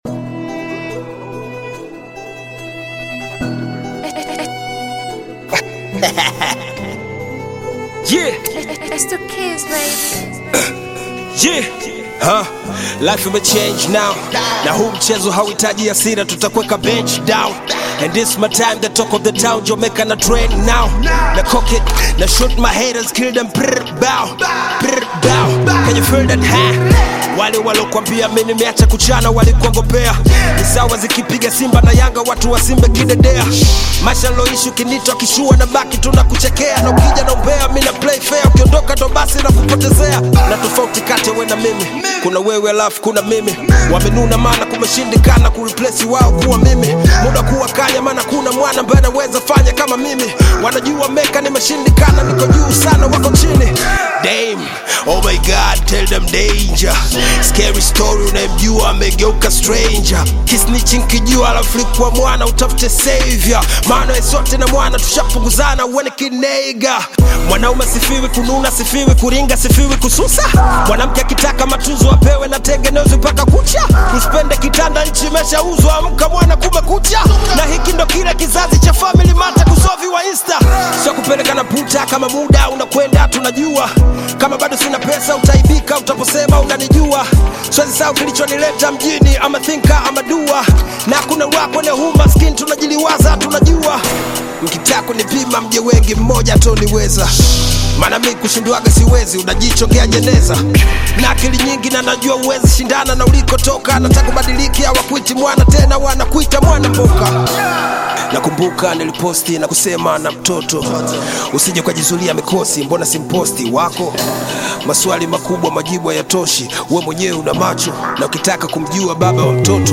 Tanzanian Bongo Flava
hip-hop song
exudes energy and vitality